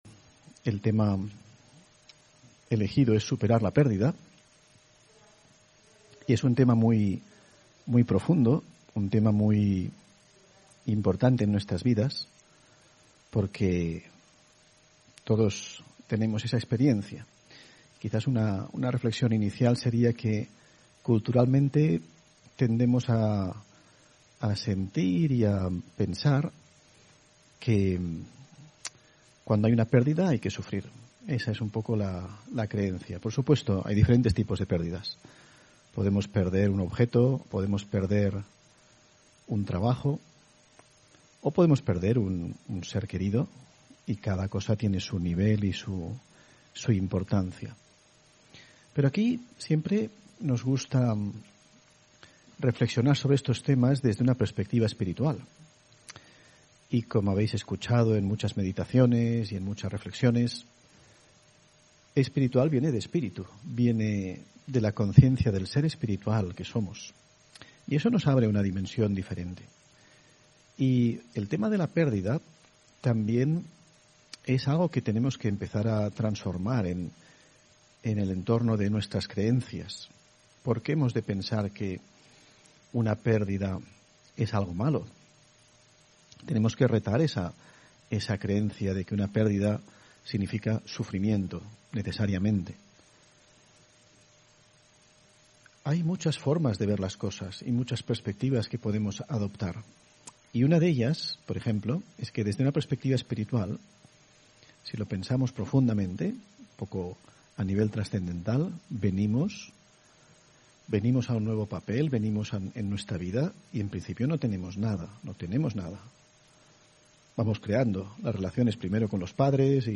Audio conferencias
Meditación y conferencia: Superar la pérdida (14 Junio 2022)